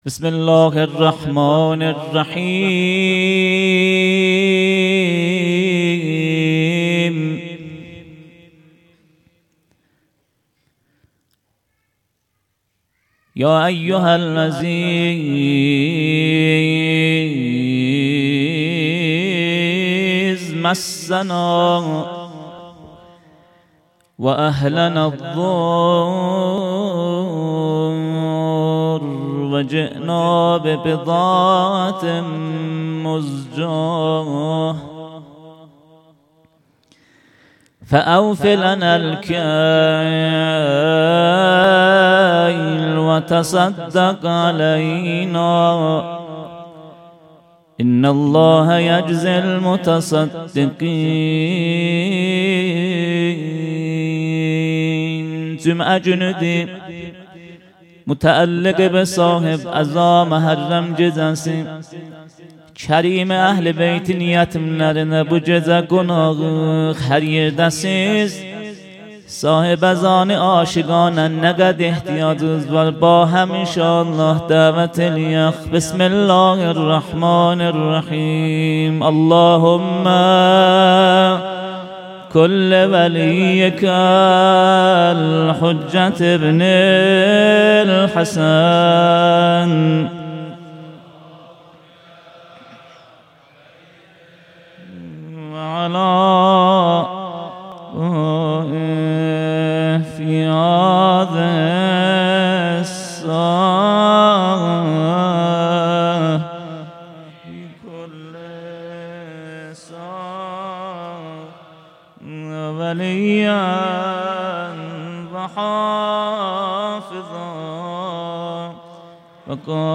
هیأت محبان اهل بیت علیهم السلام چایپاره